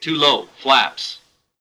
too-low-flaps.wav